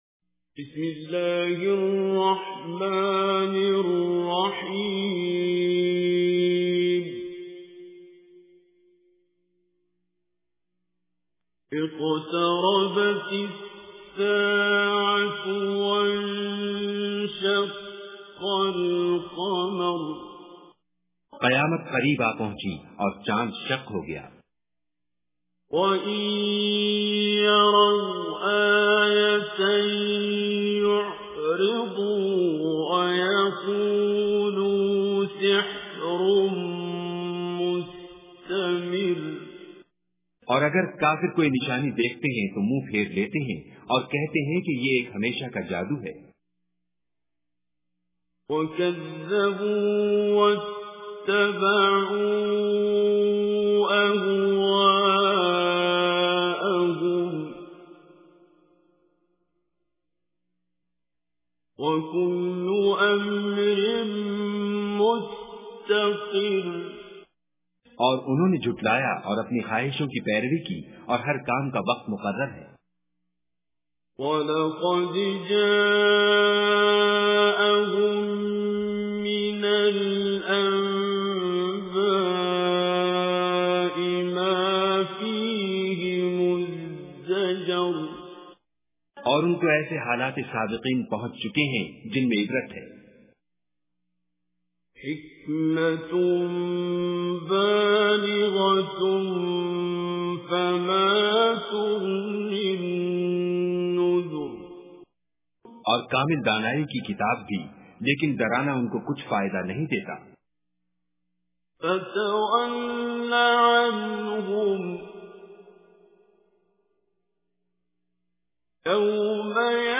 Listen online and download beautiful recitation of Surah Qamar in the voice of Qari Abdul Basit As Samad.